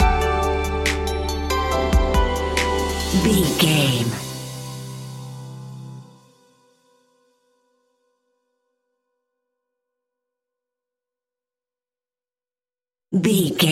Smooth Hip Hop Rap Stinger.
Ionian/Major
Slow
chilled
laid back
Deep
hip hop drums
hip hop synths
piano
hip hop pads